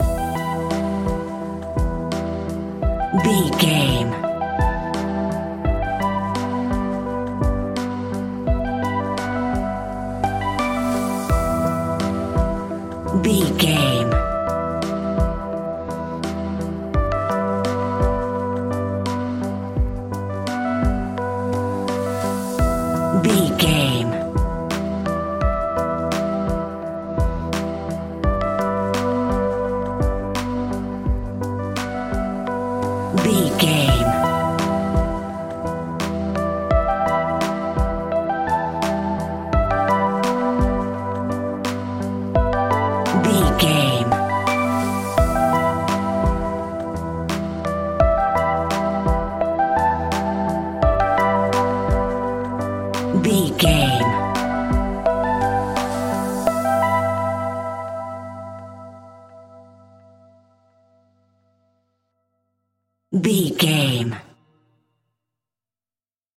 Aeolian/Minor
hip hop
instrumentals
chilled
laid back
groove
hip hop drums
hip hop synths
piano
hip hop pads